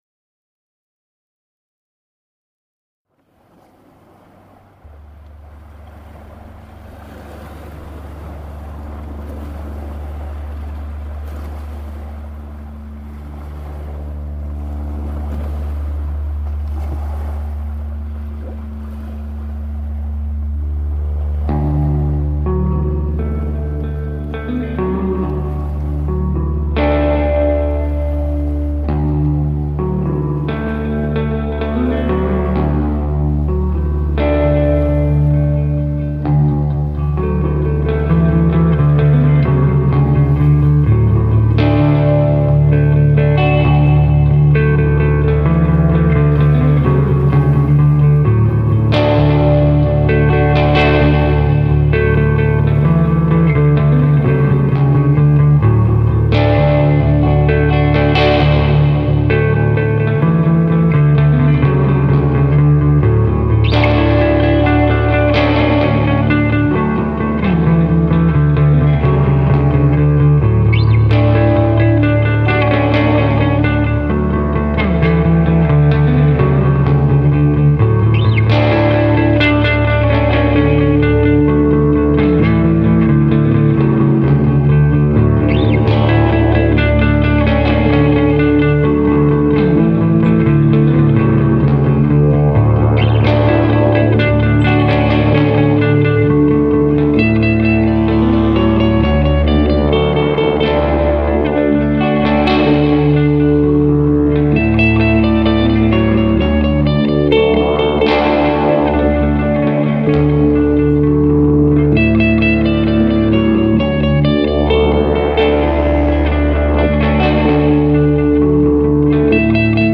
Waves on Flat Holm Island reimagined
This piece was built around the field recording of waves